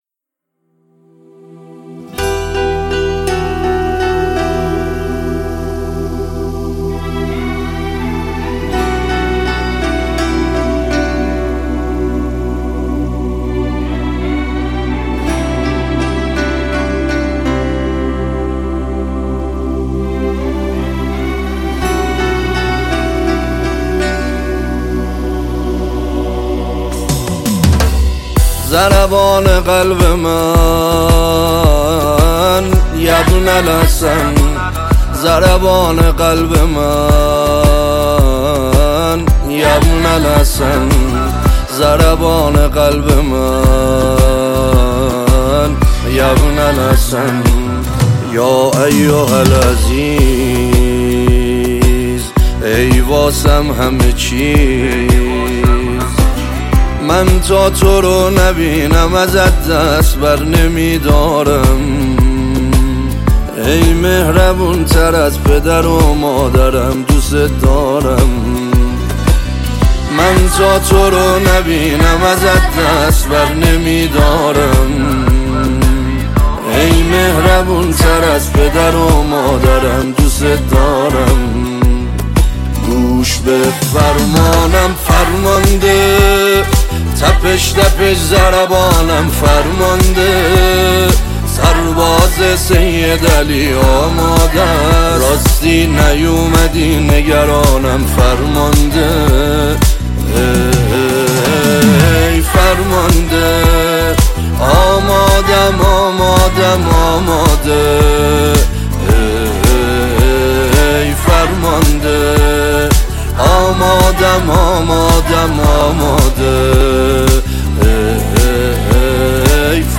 ژانر: سرود ، سرود مناسبتی